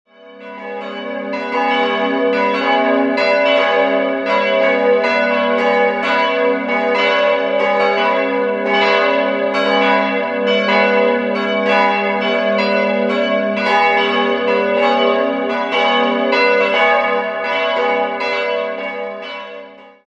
Heute wird die Pfarrei Luitpoldhöhe von der Pfarrei St. Georg seelsorglich betreut. 3-stimmiges TeDeum-Geläute: gis'-h'-cis'' Alle Glocken wurden 1950 von der Gießerei Petit&Edelbrock in Gescher (Westfalen) gegossen.